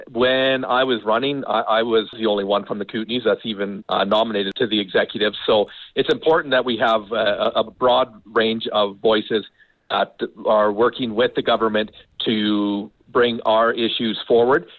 – Wes Graham – Cranbrook City Councillor, 2nd Vice President – Union of BC Municipalities Executive Team